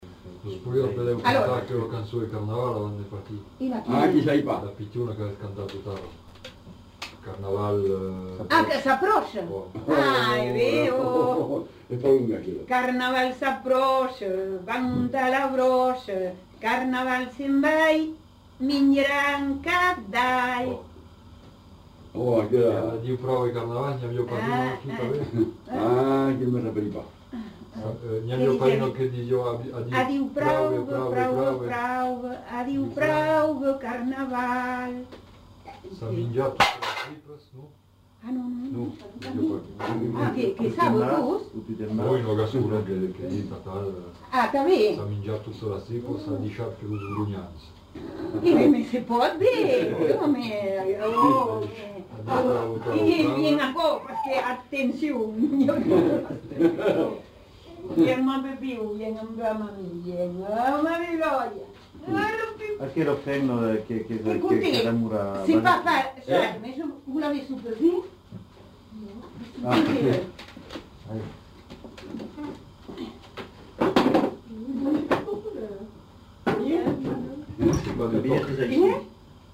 Lieu : Tonneins
Genre : chant
Effectif : 1
Type de voix : voix de femme
Production du son : chanté
Classification : chanson de carnaval